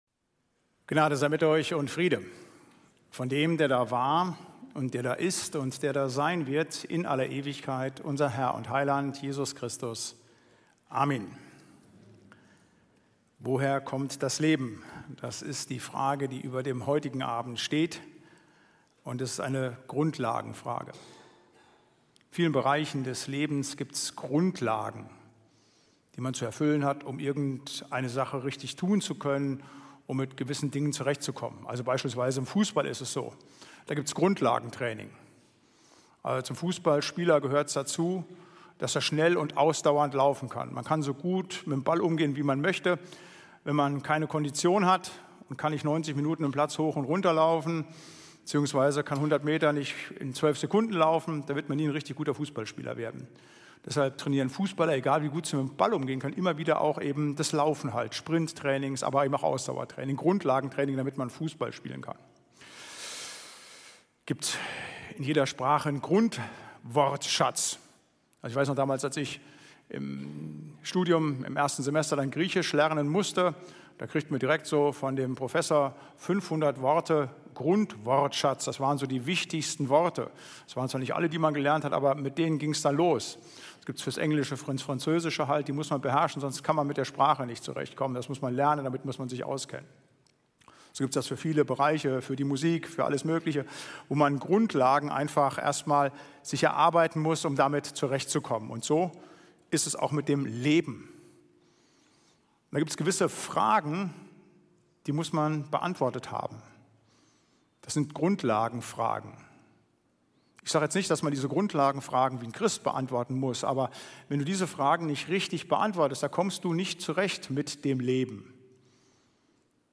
Predigt-Reihe: Lebensfragen